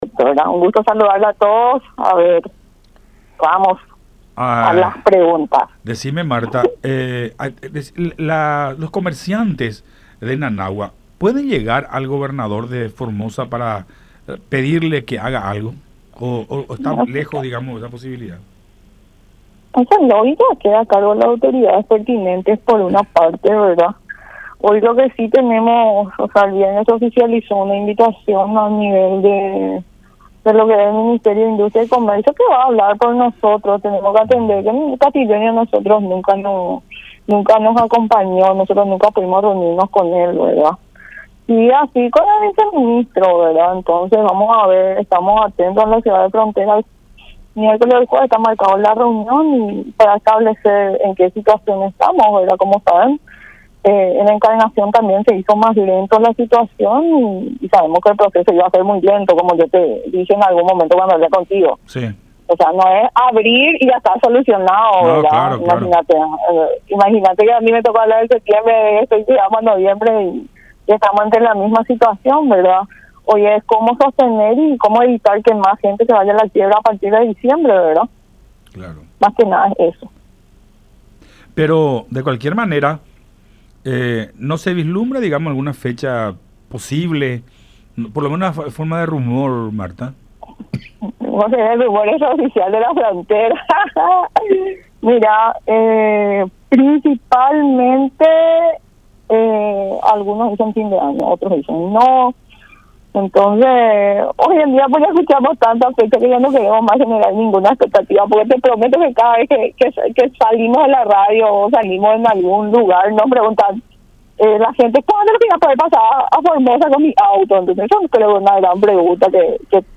en conversación con Buenas Tardes La Unión